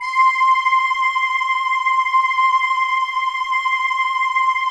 Index of /90_sSampleCDs/Optical Media International - Sonic Images Library/SI1_Soft Voices/SI1_Ozone Choir